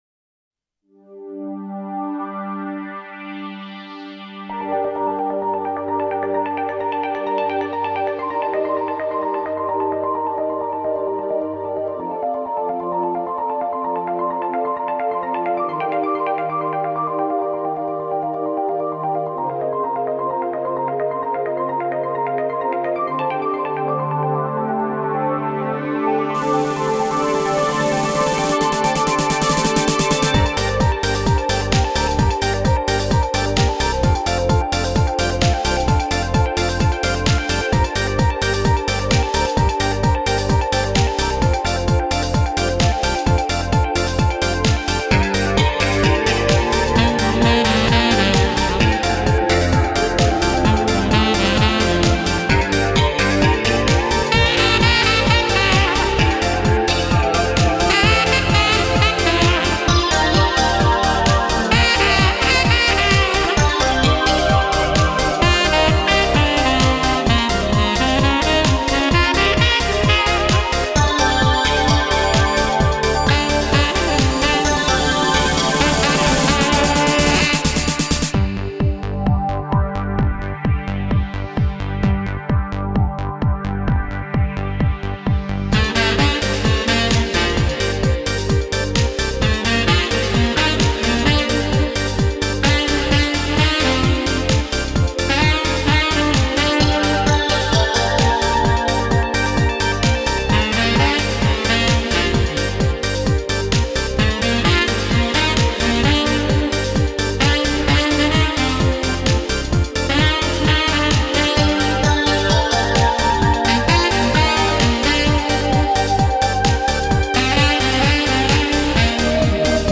Но запись достаточно чистая.